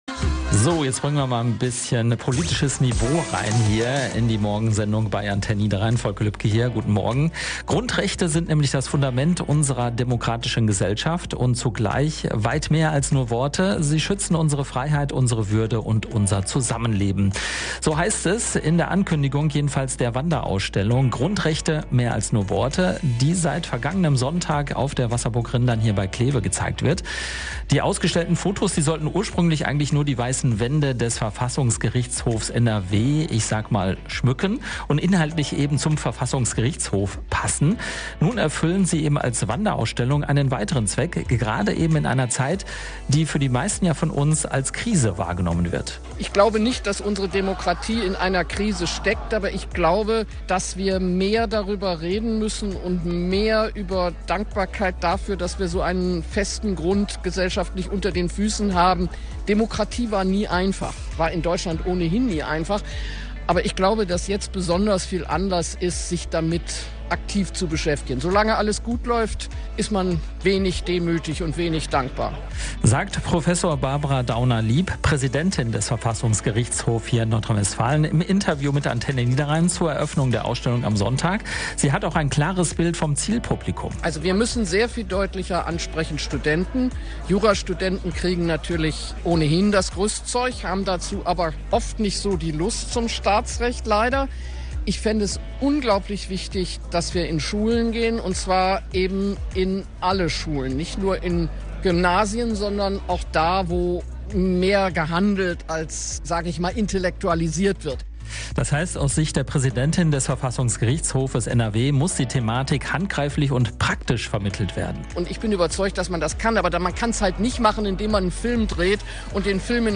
Präsidentin des Verfassungsgerichtshof NRW zur Ausstellung